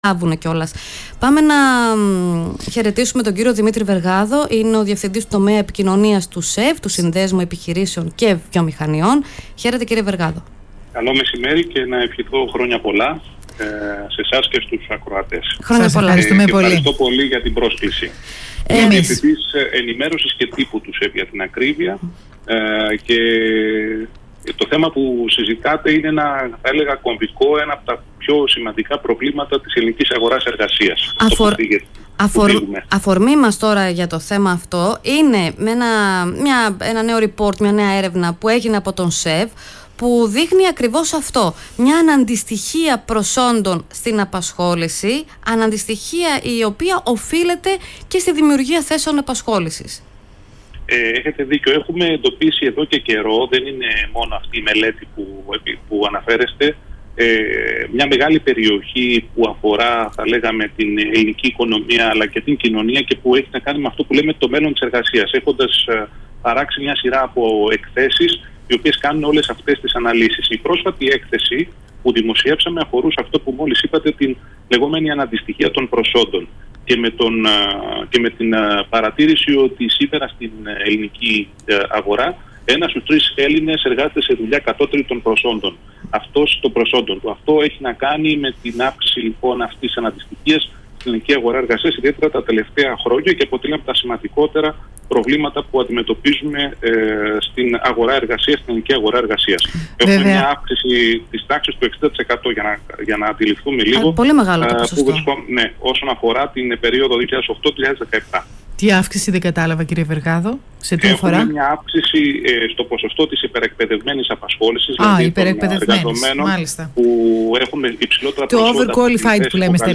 Ραδιοφωνική συνέντευξη